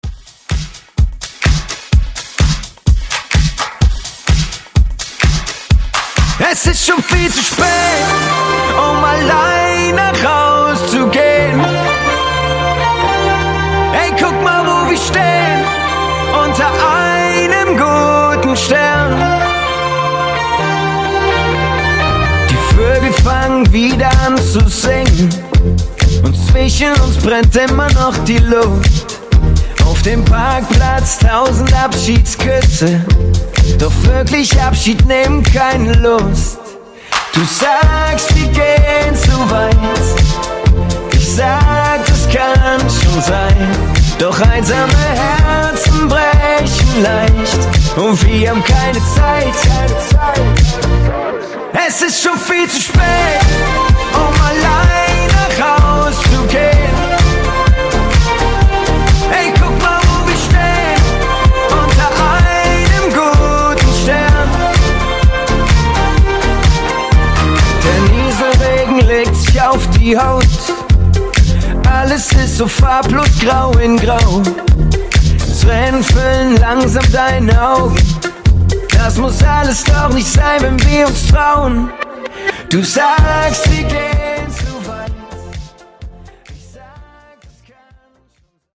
BPM: 127 Time